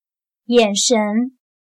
眼神/Yǎnshén/La mirada de los ojos.